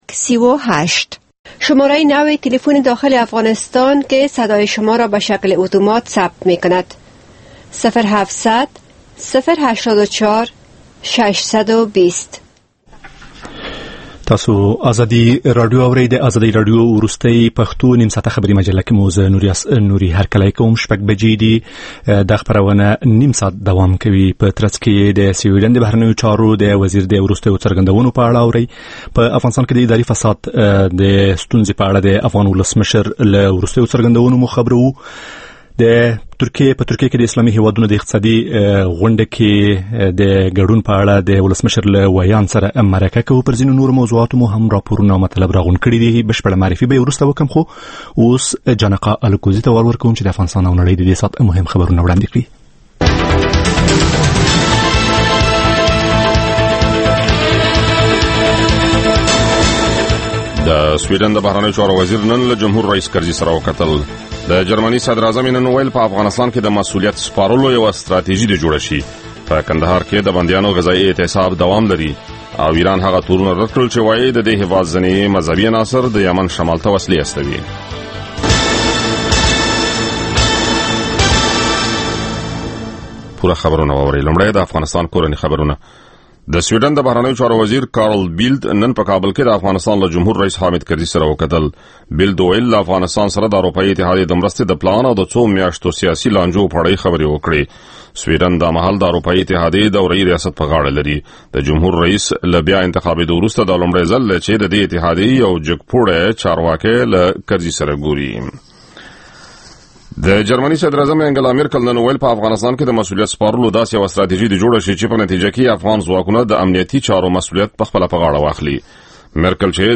ماښامنۍ خبري مجلې